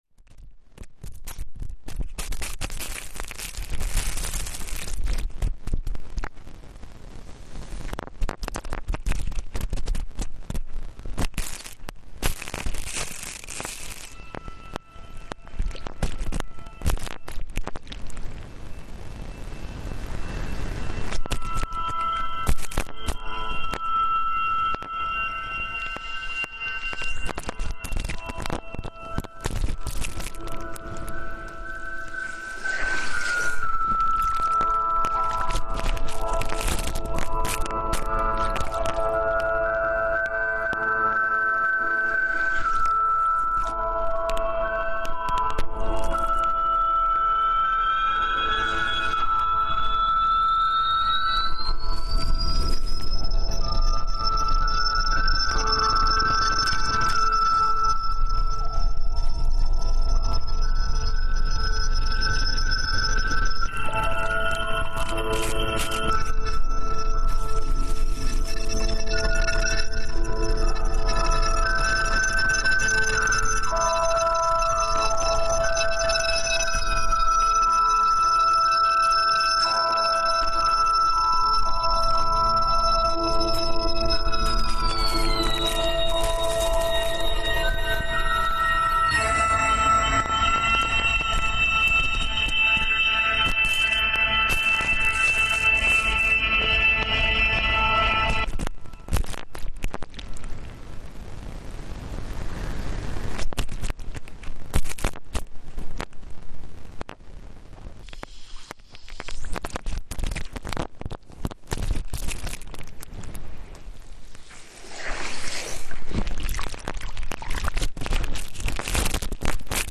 hydrophone sounds
She told me she made a hydrophone by sealing a pair of earbuds with hot silicone.
med. sea at 3pm
This evening I tried to gussy it up a bit with some granulation and some comb filters, but I think she is a natural beauty fine how she is.
Liquid gross-out.
really juicy sounds. a strange experience.